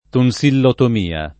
vai all'elenco alfabetico delle voci ingrandisci il carattere 100% rimpicciolisci il carattere stampa invia tramite posta elettronica codividi su Facebook tonsillotomia [ ton S illotom & a ] s. f. (med. «incisione di tonsille»)